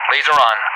LaserOn.ogg